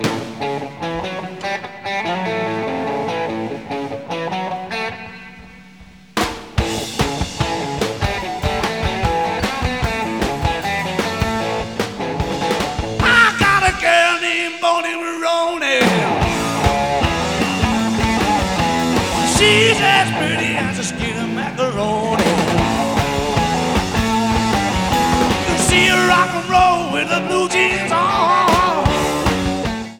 Sound Samples (All Tracks In Stereo)